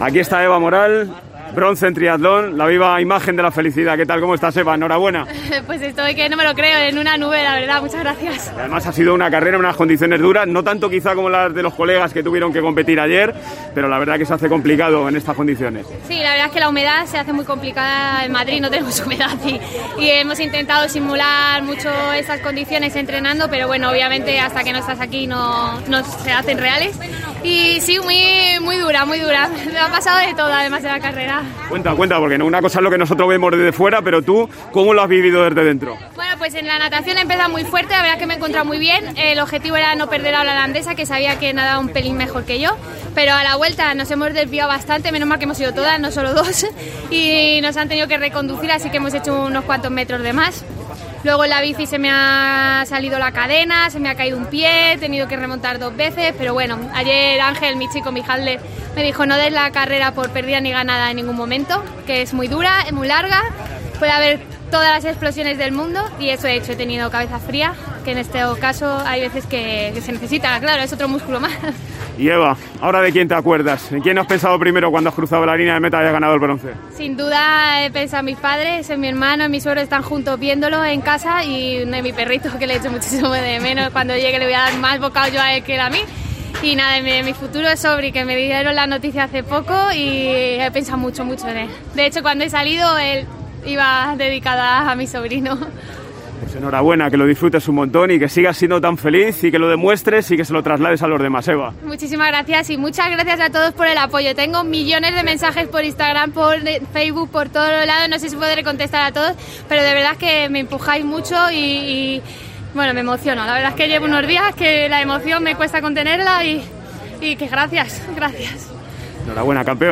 La triatleta ha contado en COPE sus sensaciones tras ganar el bronce en su debut en los Juegos Paralímpicos.